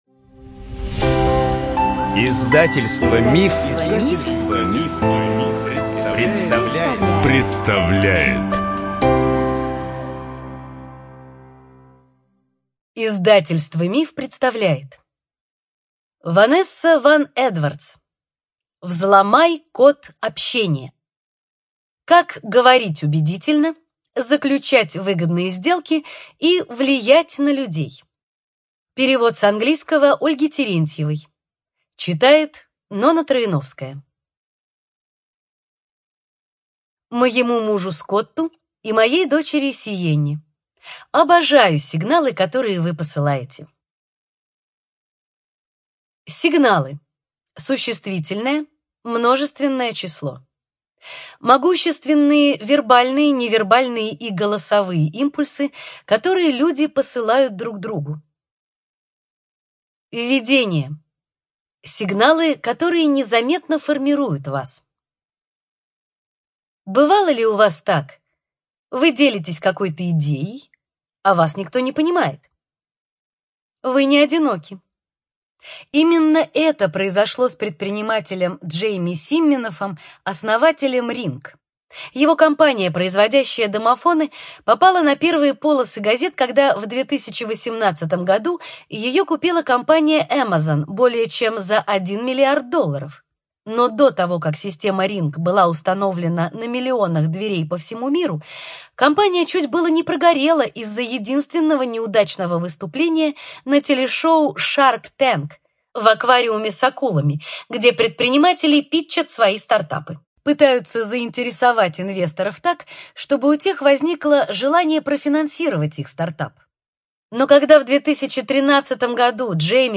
ЖанрДеловая литература